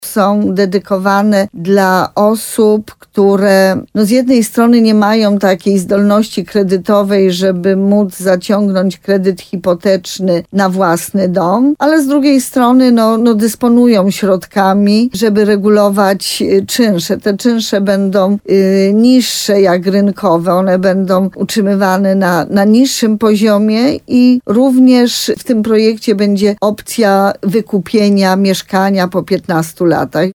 – To dobre rozwiązanie przede wszystkim dla młodych małżeństw i rodzin z dziećmi – powiedziała wójt gminy Sękowa, Małgorzata Małuch.